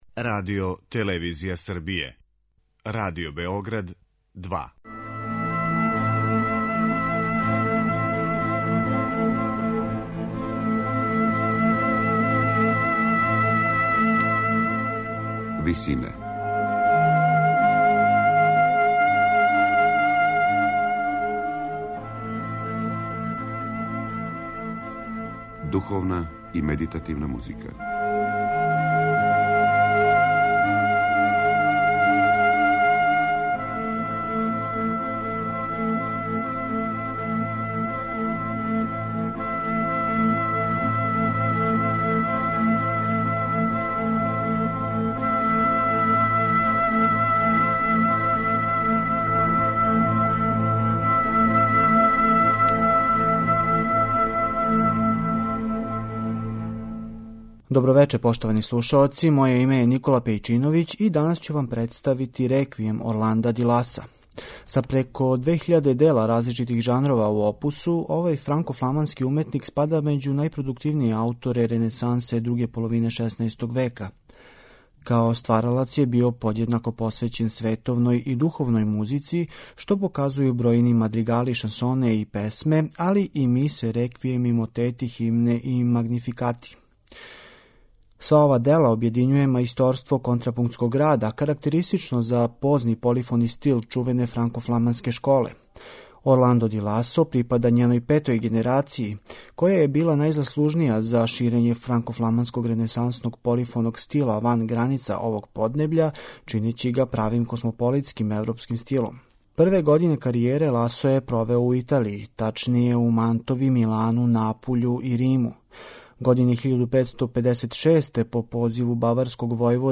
Хорско духовно остварење овог франко-фламанског композитора позне ренесансе слушаћемо у интерпретацији вокалног ансамбла Collegium Regale , којим диригује Стивен Клибери.